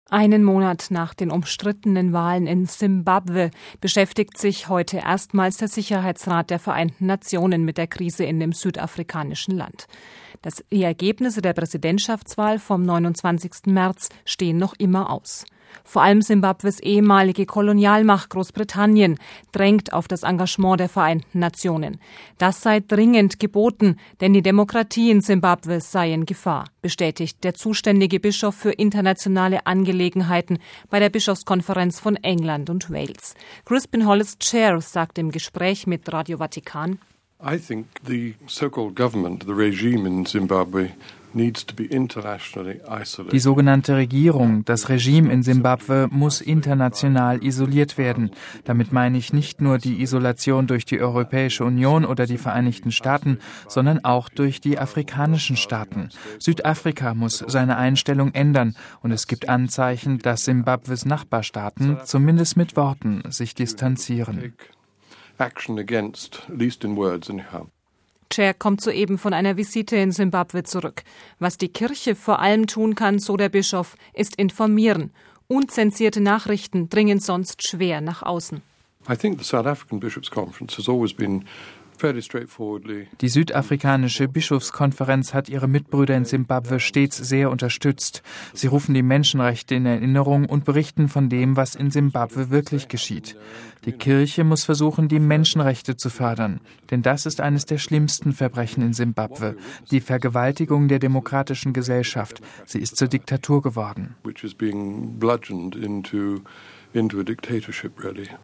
Crispin Hollis Chair sagt im Gespräch mit Radio Vatikan: